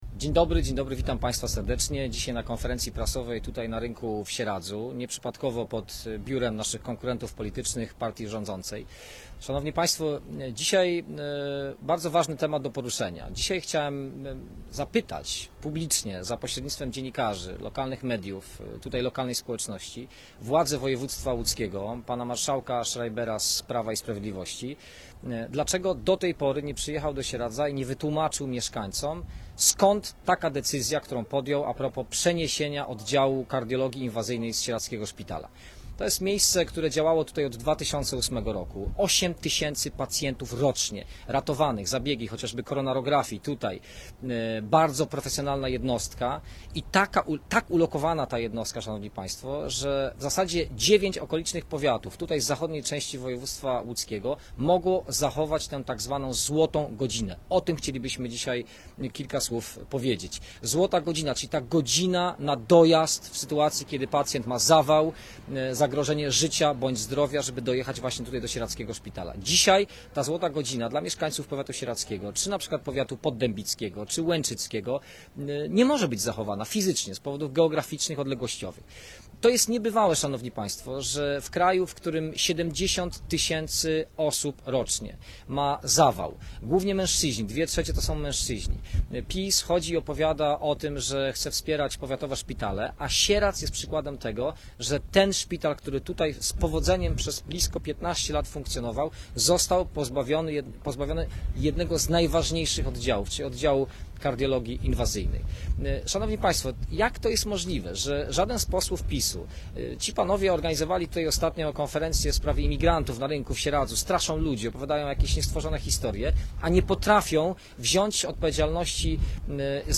W środę (11 października) Mikołaj Dorożała, kandydat Trzeciej Drogi do Sejmu, mówił w Sieradzu o konieczności utworzenia kardiologii inwazyjnej w szpitalu wojewódzkim.